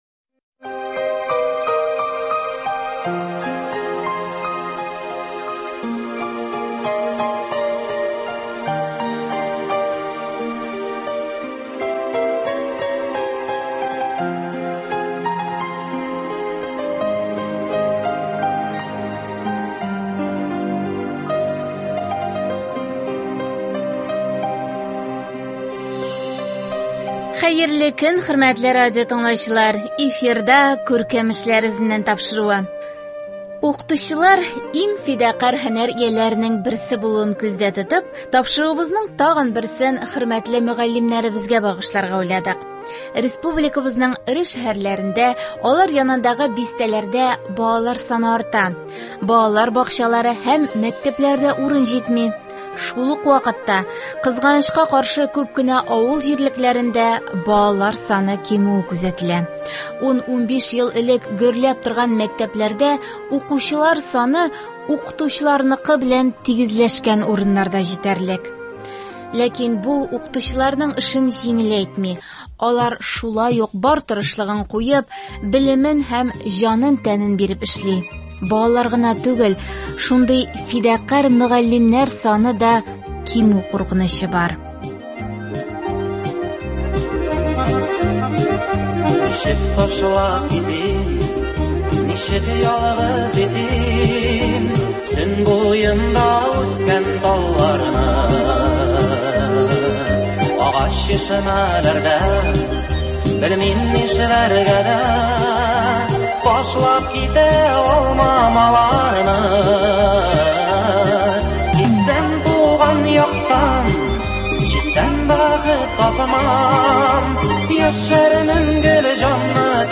Бу тирәдәге татар авылларыннан балалар шушы Акъегеткә килеп белем ала. Бүгенге тапшыруыбызны без әлеге мәктәптән әзерләдек.